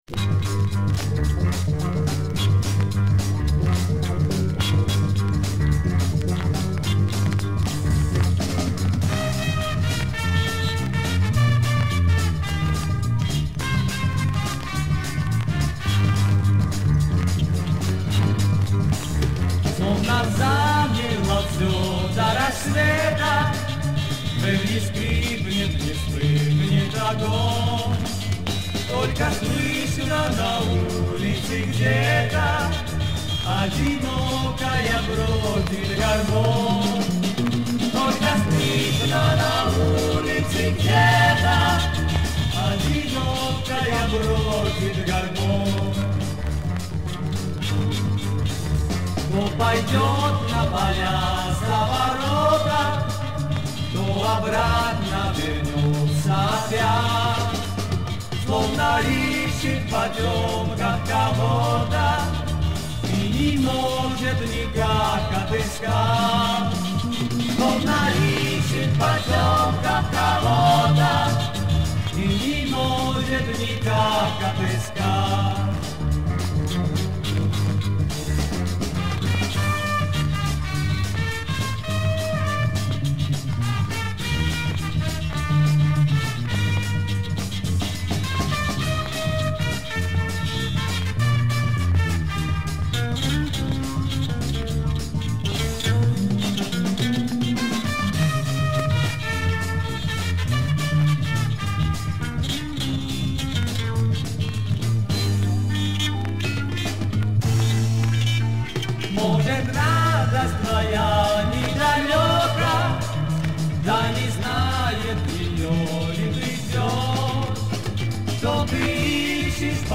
Запись не изменена.